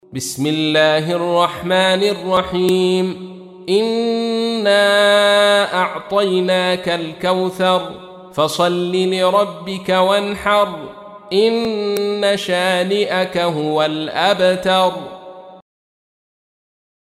تحميل : 108. سورة الكوثر / القارئ عبد الرشيد صوفي / القرآن الكريم / موقع يا حسين